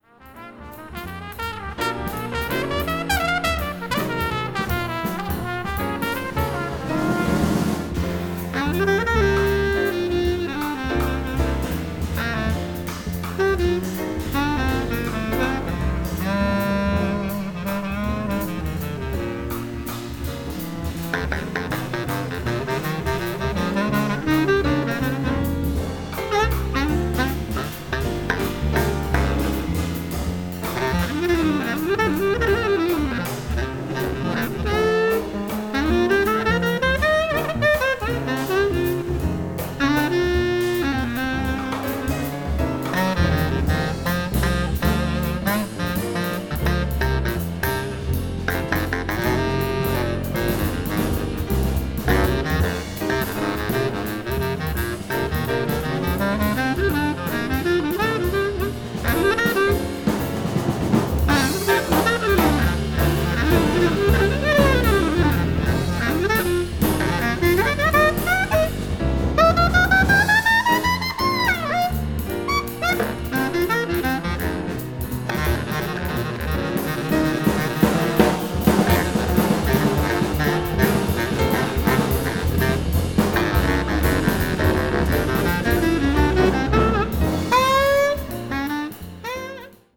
trumpet
trombone
alto saxophone
piano
bass
emphasizing ensemble playing